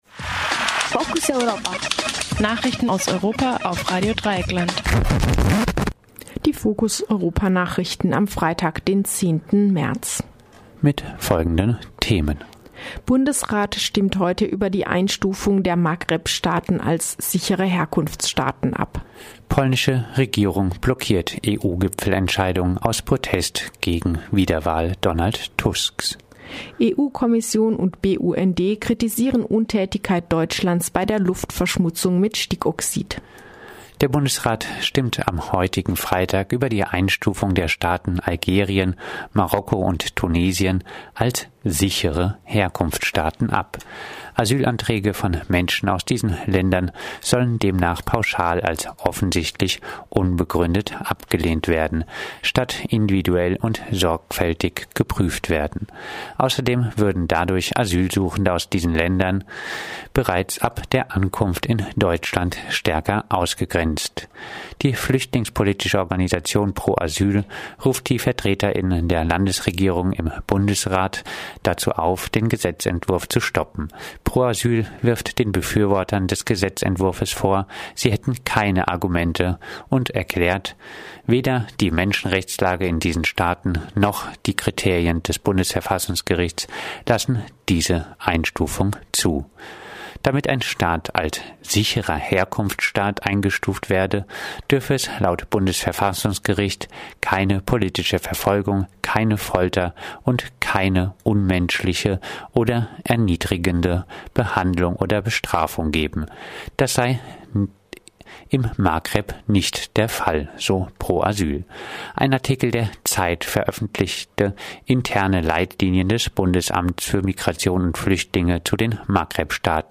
Focus Europa Nachrichten am Freitag, 10. März 2017 um 9:30